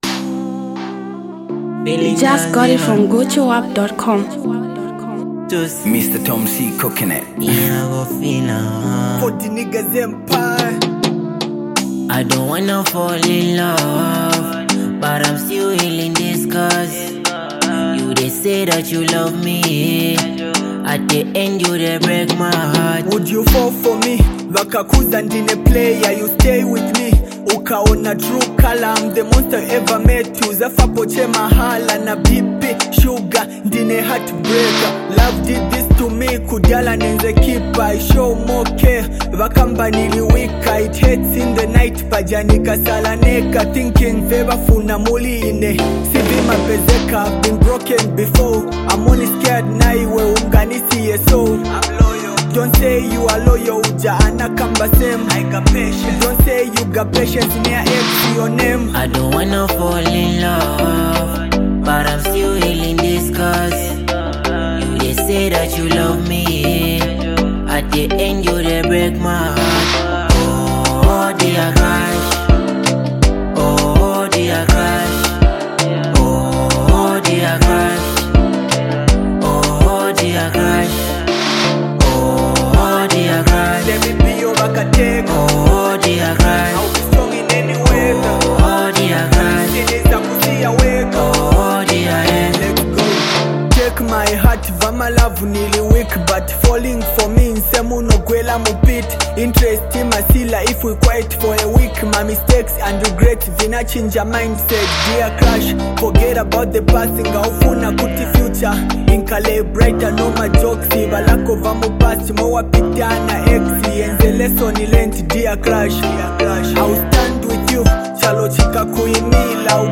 ” a heartfelt song about love, heartbreak, and healing.